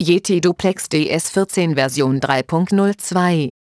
Sprachansagetext für neue Version V3.02